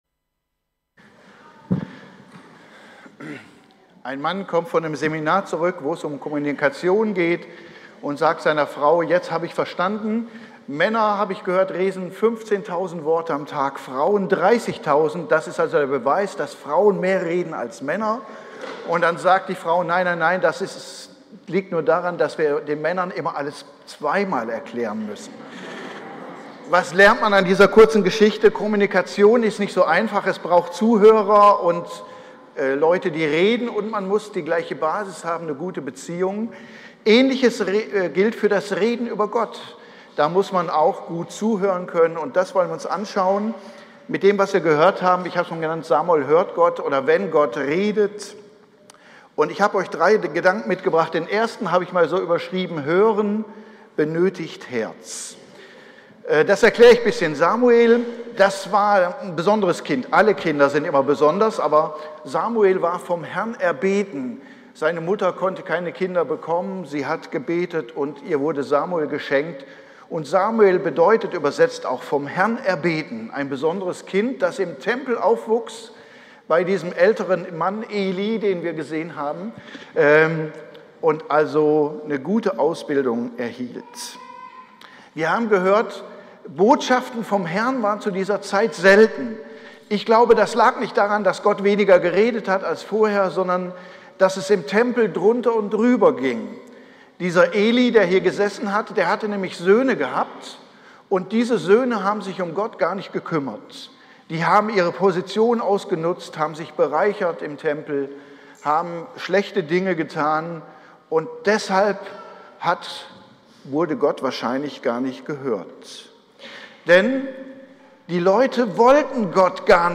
Samuel 3-18 – Freie evangelische Gemeinde Münster